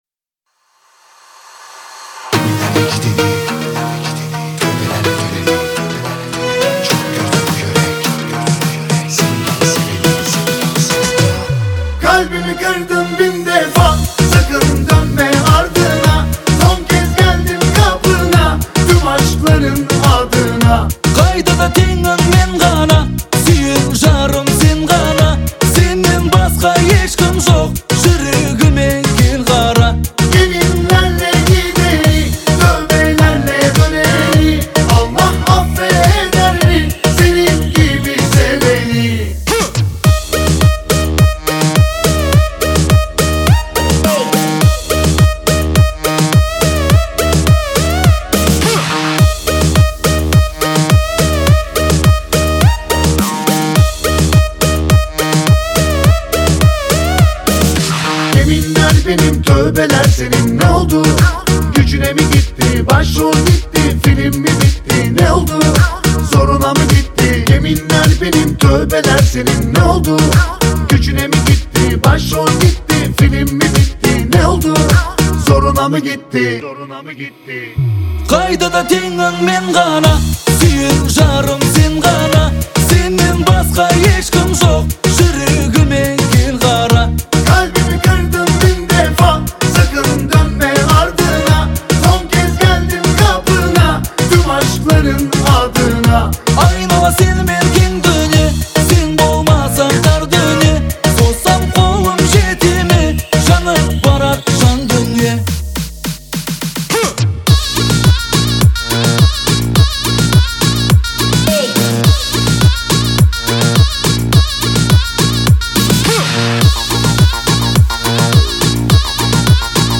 это трогательный поп-балладный дуэт